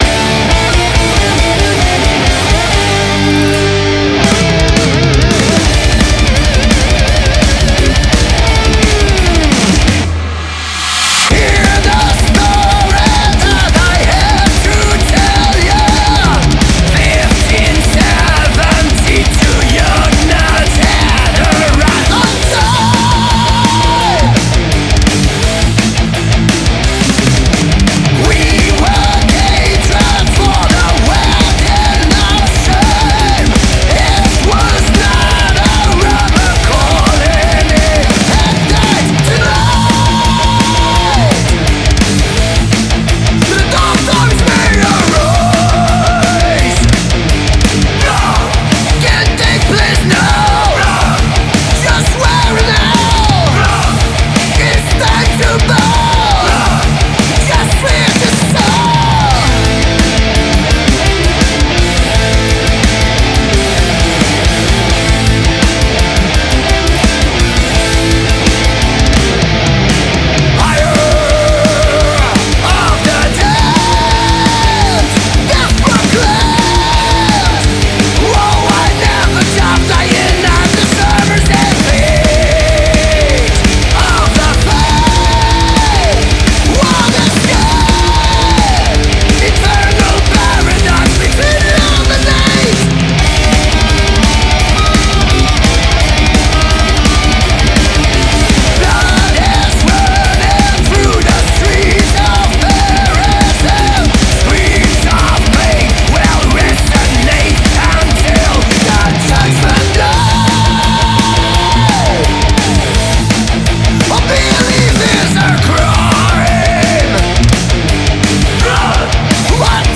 heavy metal Belgique